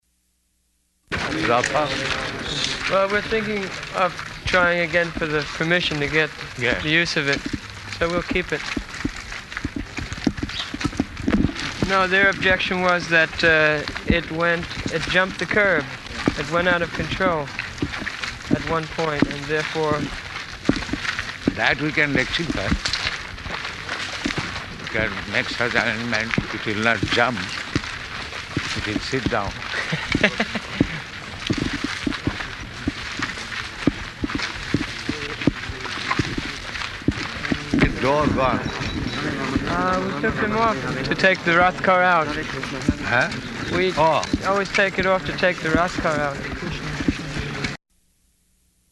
Morning Walk [partially recorded]
Type: Walk
Location: London